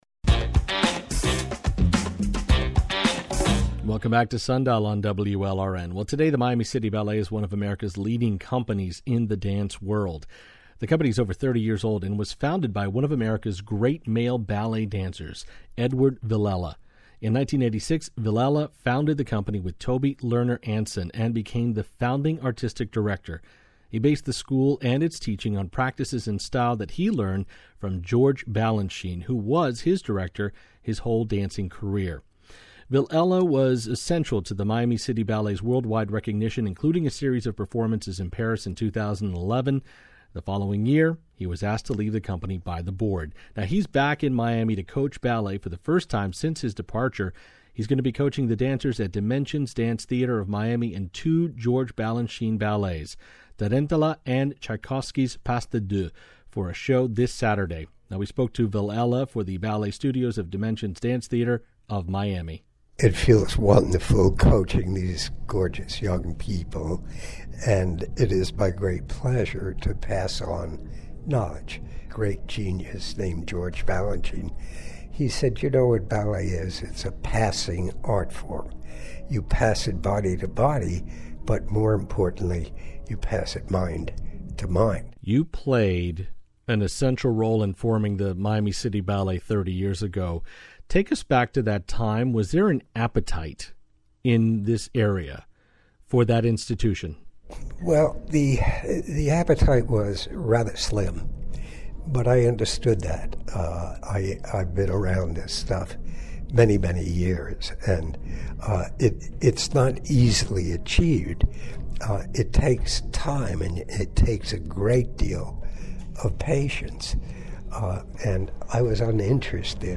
Listen to the full interview with ballet legend, Edward Villella.